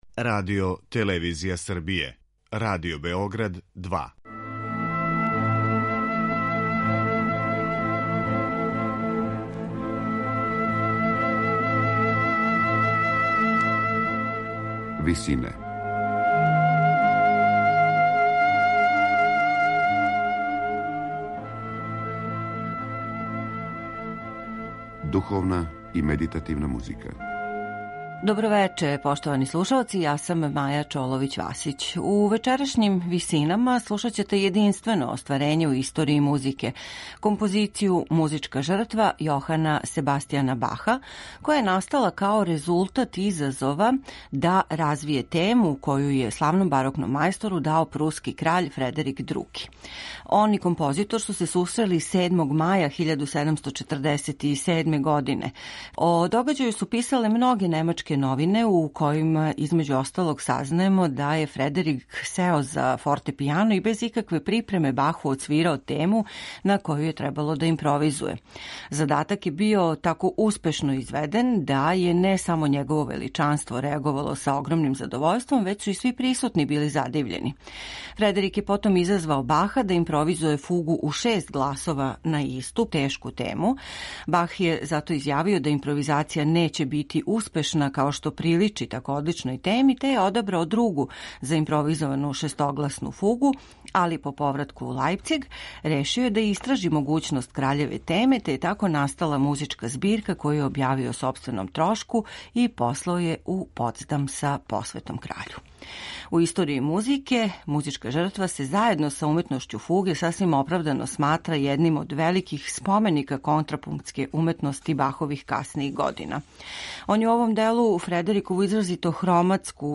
Дело које чини трио соната и низ ричеркара и канона посветио је краљу на чију је тему написао све пометнуте композиције.
Осим трио сонате, која је компонована за флауту, виолину и континуо, за остале композиције није назначено којим су инструментима намењене.